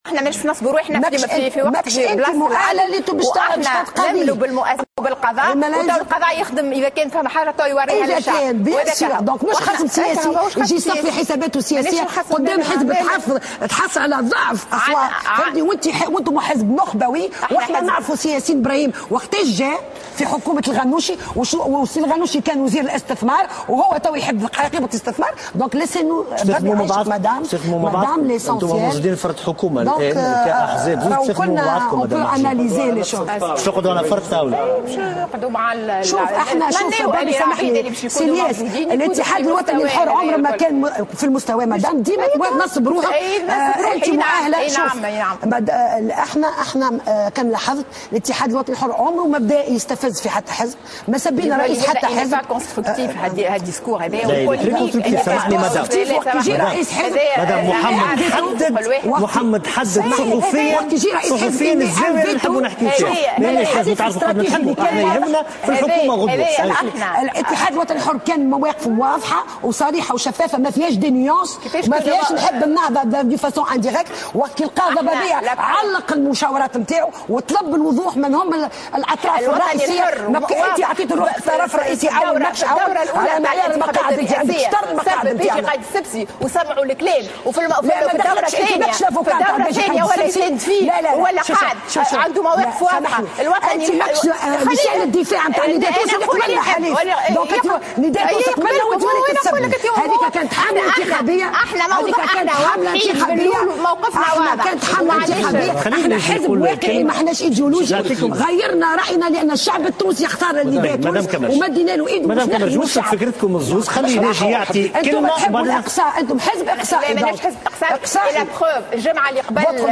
مشادة كلامية بين ريم محجوب و أحلام كمرجي
نشبت اليوم الاثنين مشادة كلامية بين ممثلة حزب الاتحاد الوطني الحر أحلام كمرجي وممثلة حزب أفاق تونس ريم محجوب على قناة الحوار التونسي.